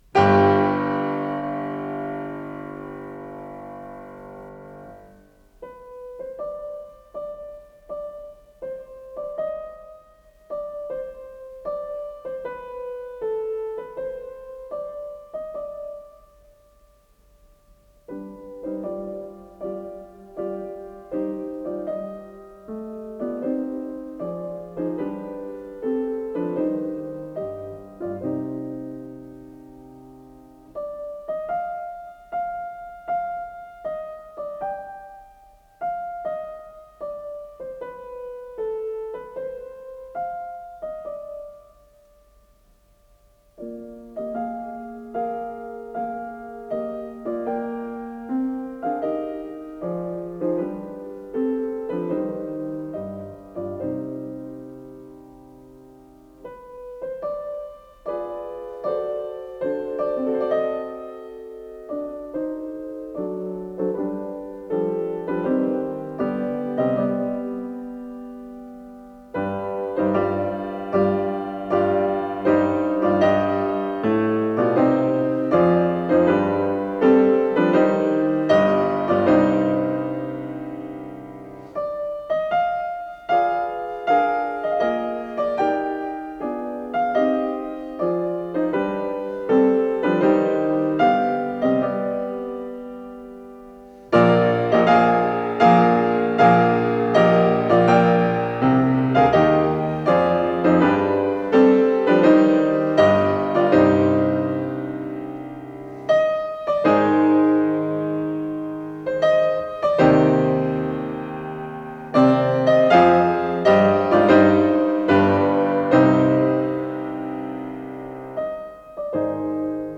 ПодзаголовокСоч. 90, до минор
ИсполнителиОлег Бошнякович - фортепиано
ВариантДубль моно